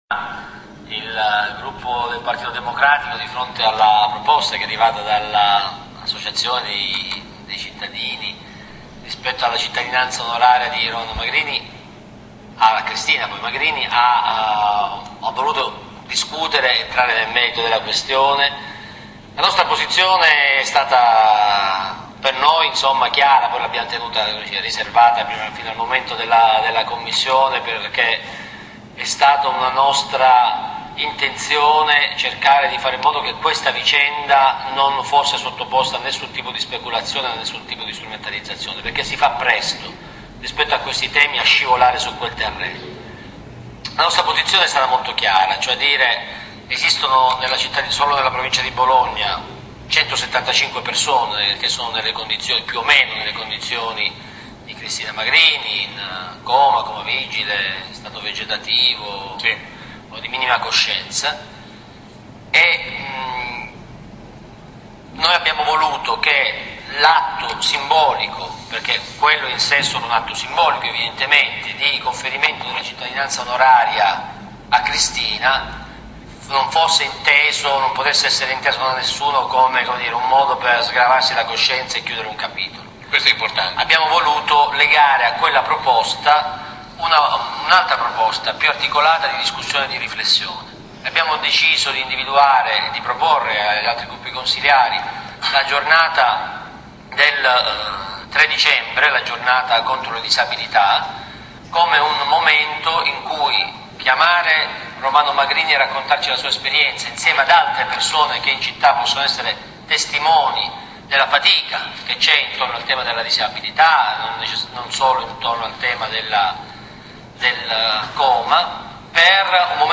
Dedalus - Intervista al capogruppo PD Sergio Lo Giudice il 18 novembre